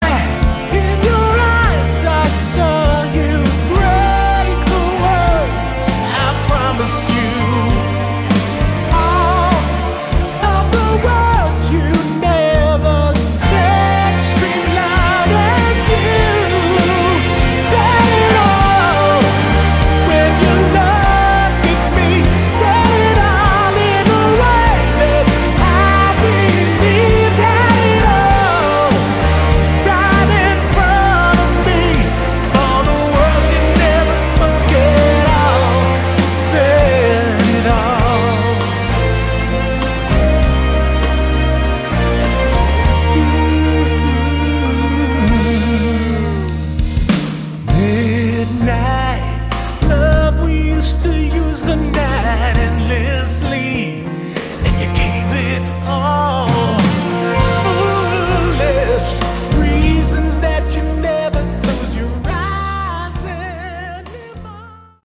Category: AOR/ Melodic Rock